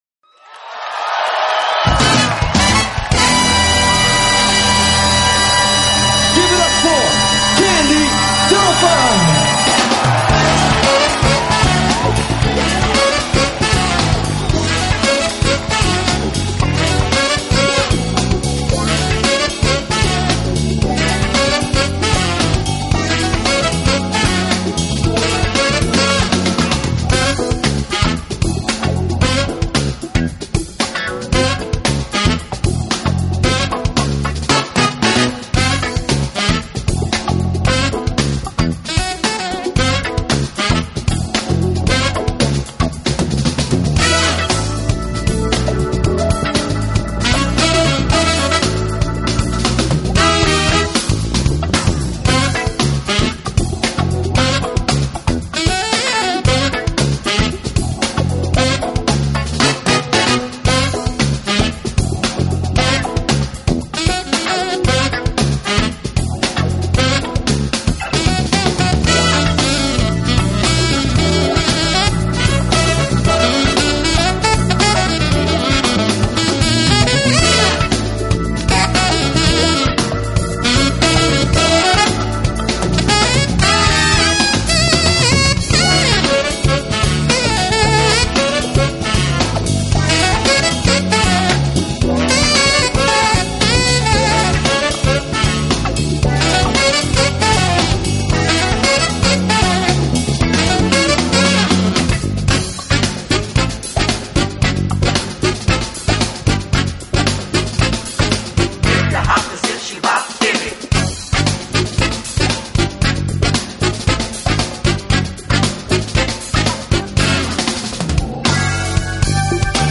面庞，她吹奏中音萨克斯的技巧也堪称一流。